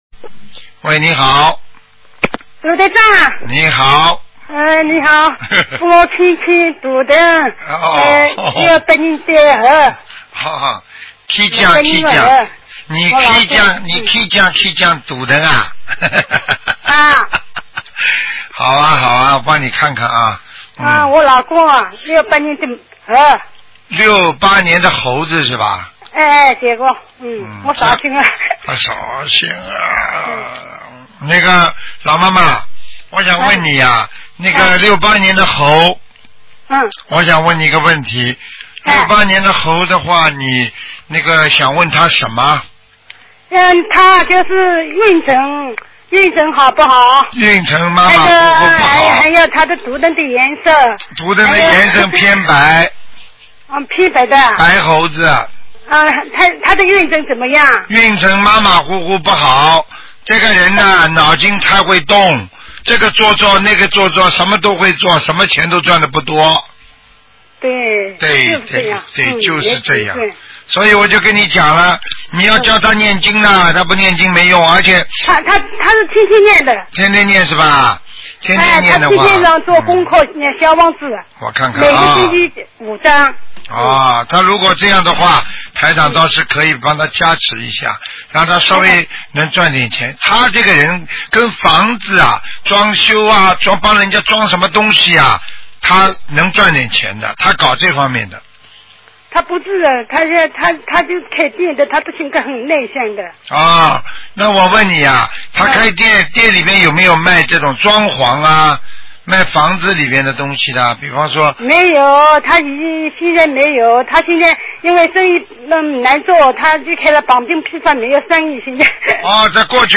目录：2014年_剪辑电台节目录音集锦